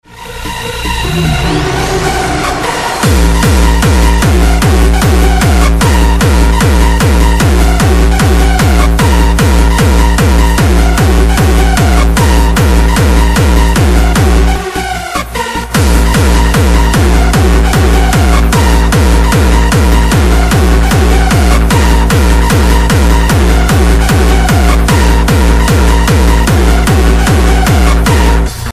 Звуки очистки динамиков
Аудиофайлы генерируют специфические частоты, которые помогают вытолкнуть загрязнения без механического вмешательства.